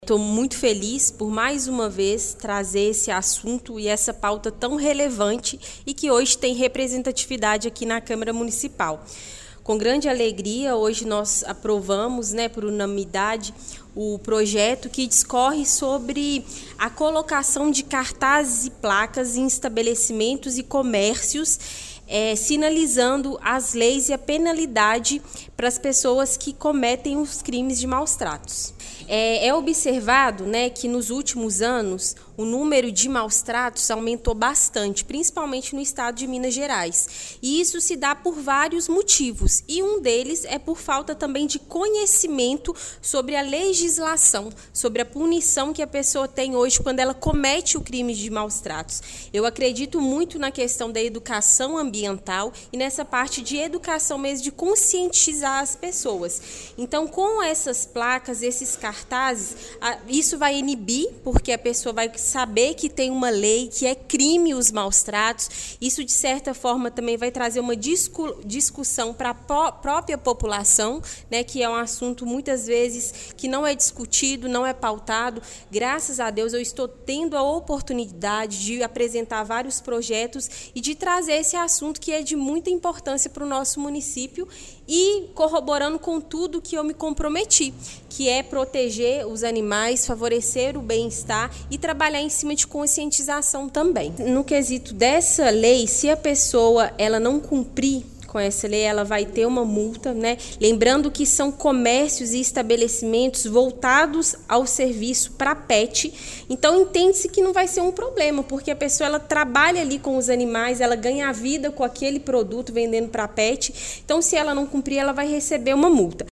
Em sua fala após a aprovação, Camila Gonçalves de Araújo destacou que o projeto é, acima de tudo, uma ferramenta educativa e de conscientização. A vereadora também reforçou que a exposição das informações ajudará a criar uma cultura de proteção animal, além de empoderar a população para agir diante de situações de crueldade: